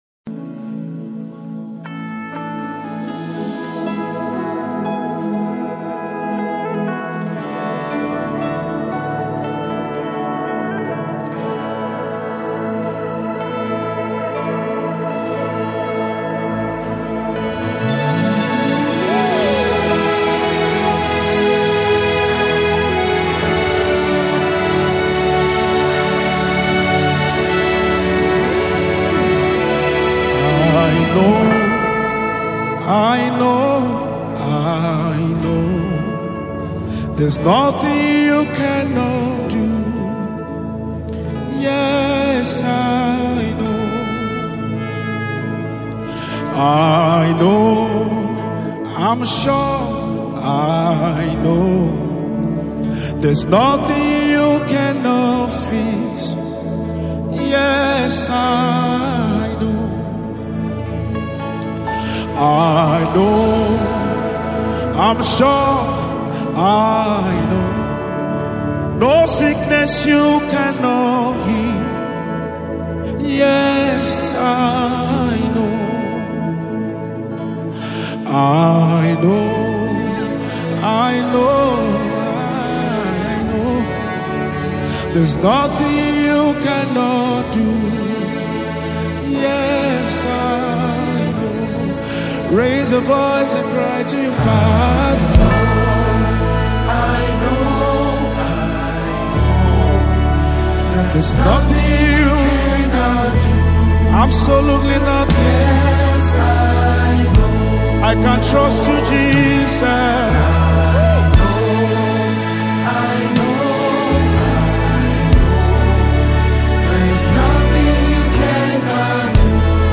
a powerful gospel song